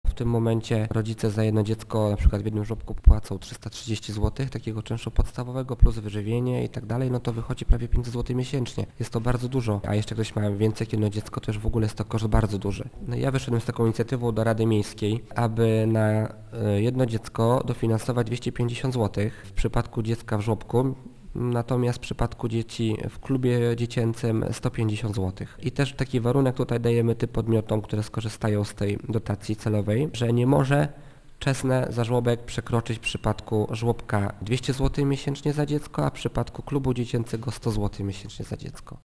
Dlatego jedną z pierwszych moich decyzji po objęciu funkcji burmistrza jest właśnie złożenie projektu uchwały, wprowadzającej dotację celową dla podmiotów, prowadzących żłobki i klub dla maluchów, która powinna odciążyć kieszenie rodziców dzieci - mówi Wiesław Muszyński: